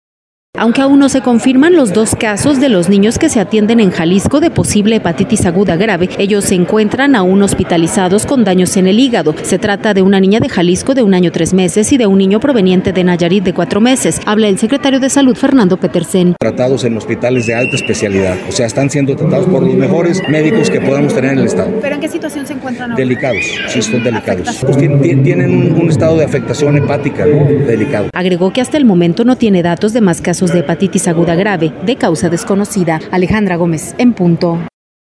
Habla el secretario de Salud, Fernando Petersen.